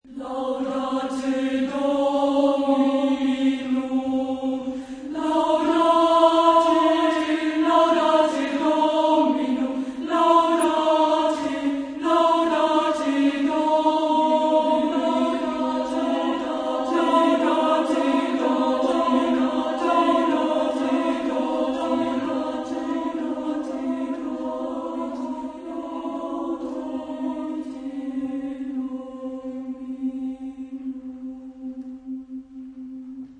SSAA (4 voix égales de femmes).